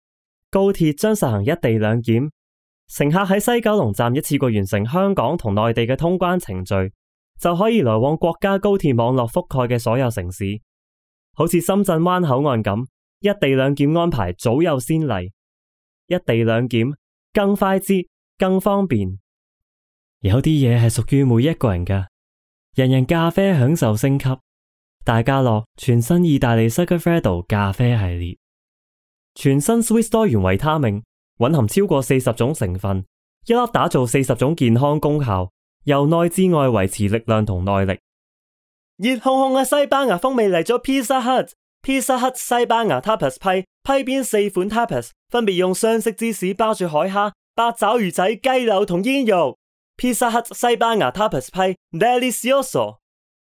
Male
Yng Adult (18-29)
I am native in speaking Cantonese (perfect Hong Kong accent).
All our voice actors have professional broadcast quality recording studios.
0924Advertisement_Demo_3.mp3